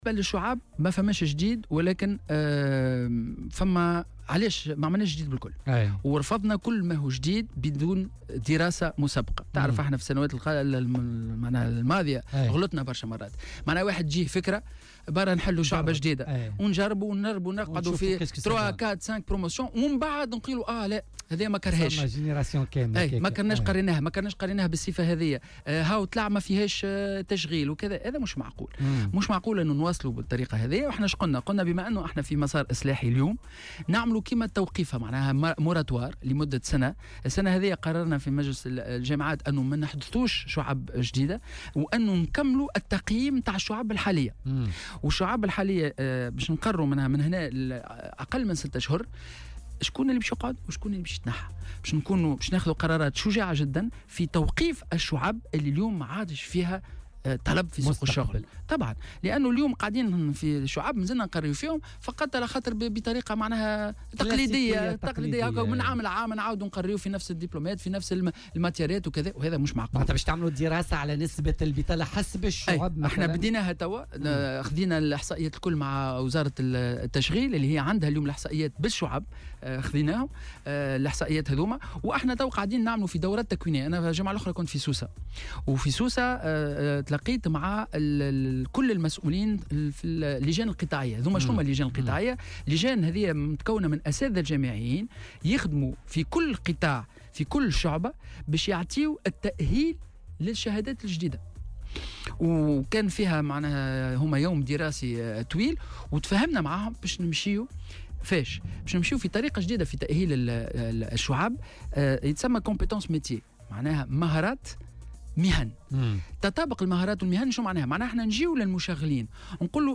وقال خلبوس، ضيف برنامج "بوليتيكا" اليوم الثلاثاء : سنتخذ أيضا قرارات شجاعة لإلغاء بعض الشعب التي لا يمكن أن توفر فرص شغل".وأوضح الوزير أنه لن يتم إحداث شعب جديدة دون تقييم ودراسة مسبقة، مشيرا إلى أن الوزارة بصدد إعداد دراسة حول نسب البطالة حسب الشعب وذلك بالتعاون مع التشغيل.